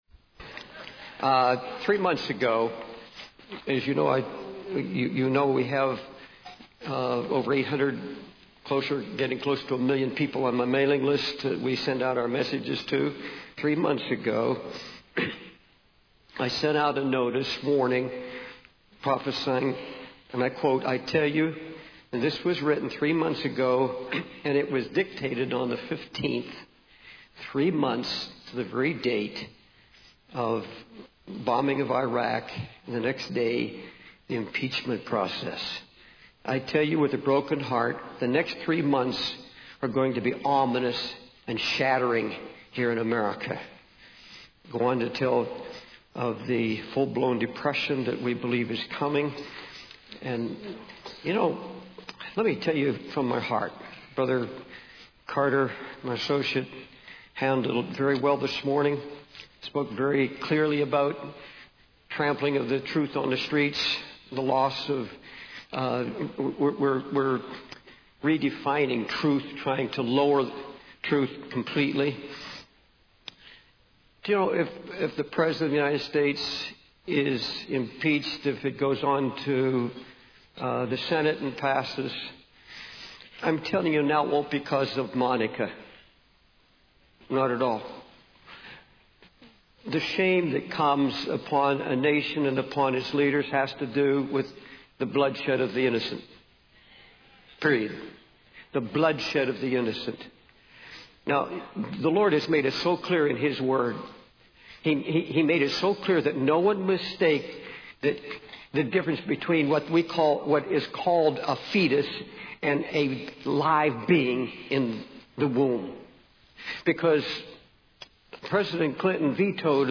We Have Seen His Star by David Wilkerson | SermonIndex